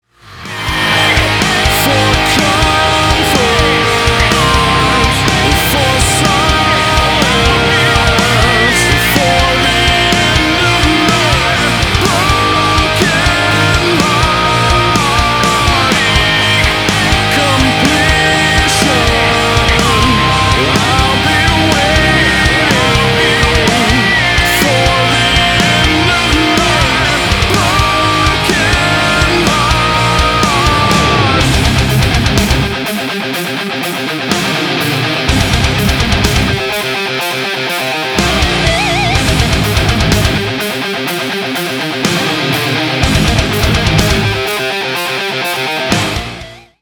гитара
мужской вокал
Драйвовые
Metalcore
соло на электрогитаре
melodic metalcore